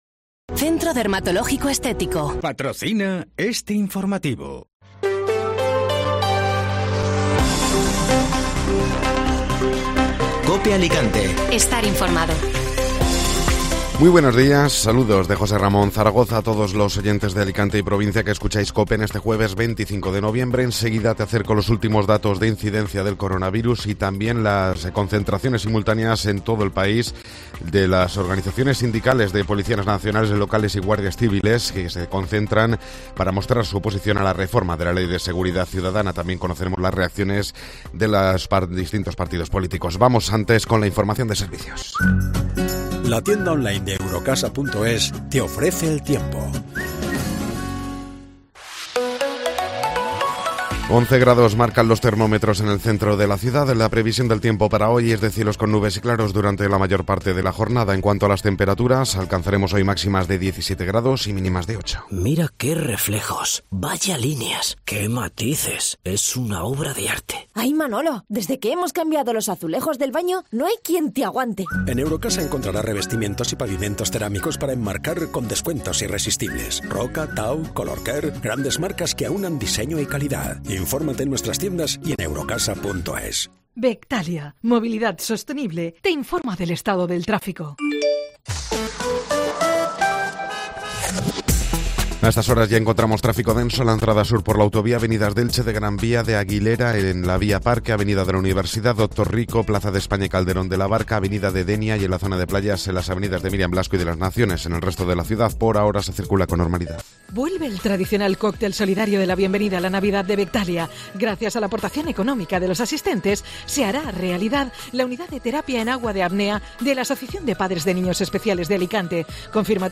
Informativo Matinal (Jueves 25 de Noviembre)